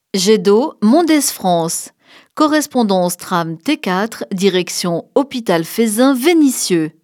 Annonce tram T2